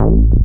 bseTTE48012moog-A.wav